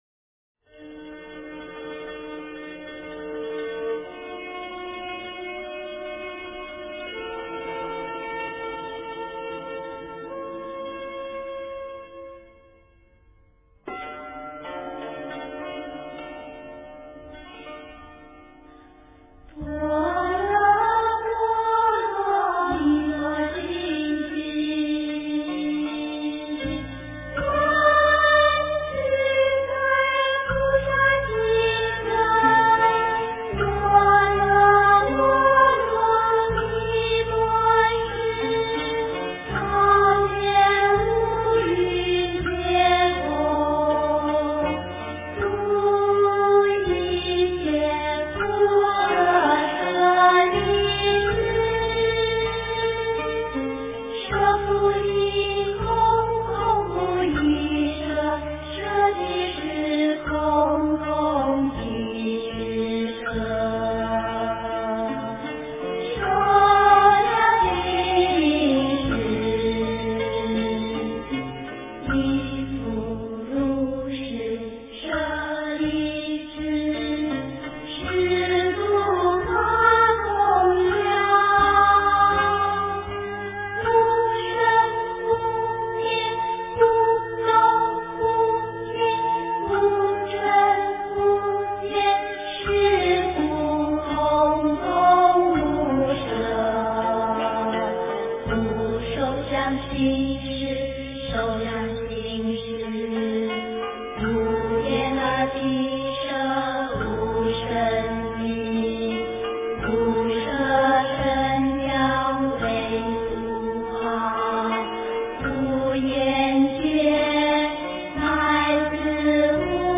心经 诵经 心经--佚名 点我： 标签: 佛音 诵经 佛教音乐 返回列表 上一篇： 心经 下一篇： 般若波罗蜜多心经 相关文章 貧僧有話9說：我怎样走上国际的道路--释星云 貧僧有話9說：我怎样走上国际的道路--释星云...